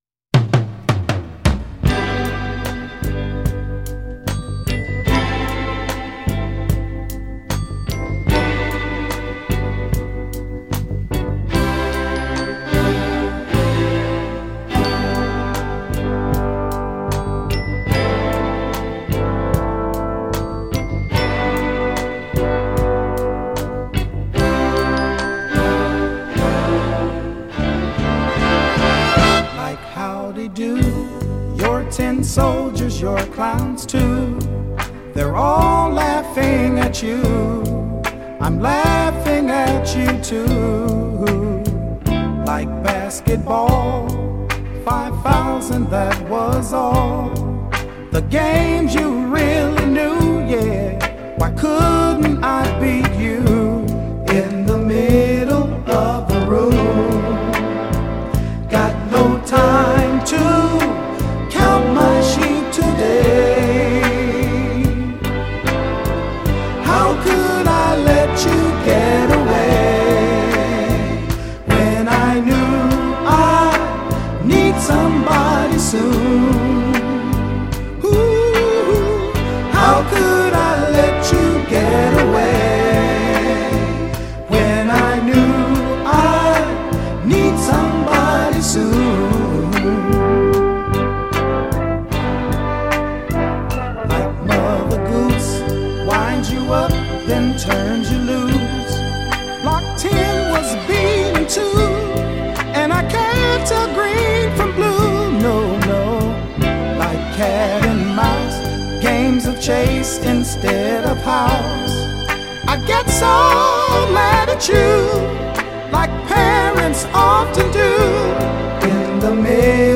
gorgeous R&B ballad